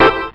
confirm.wav